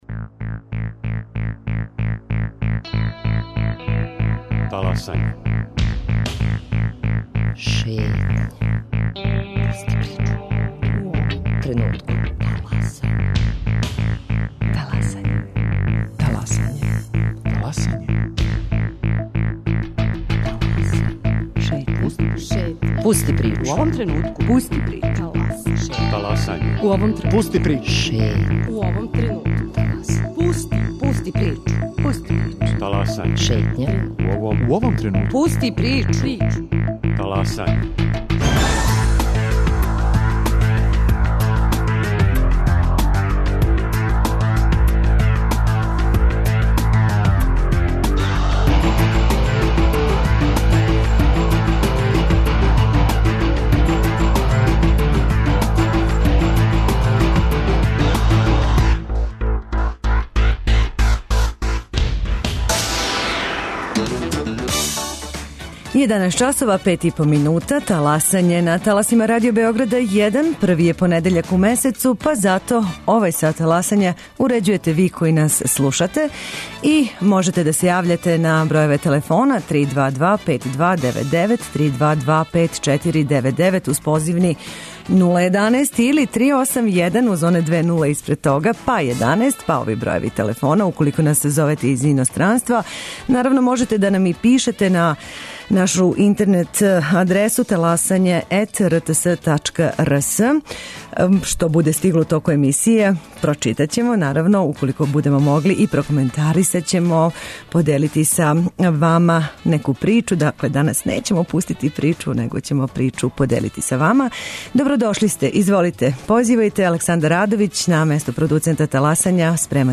Контакт програм Радио Београда 1! Ваше предлоге, примедбе и похвале реците јавно, а ми ћемо слушати пажљиво!